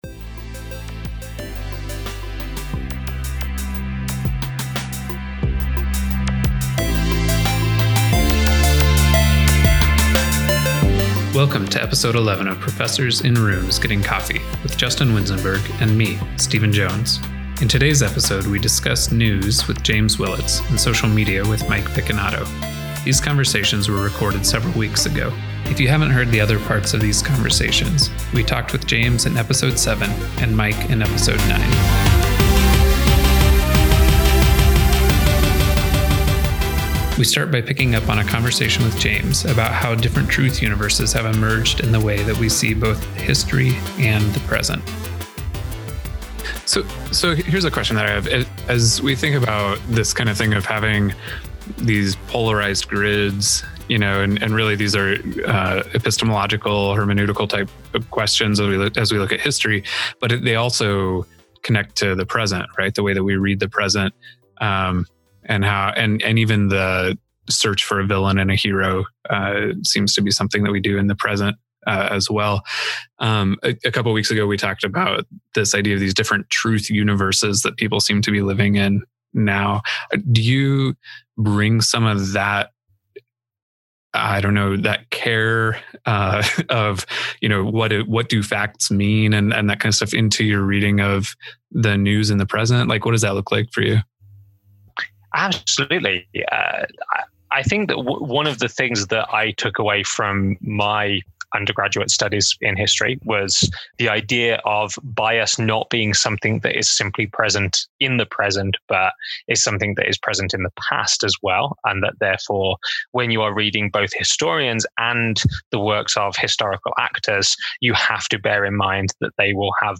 We present continuations of conversations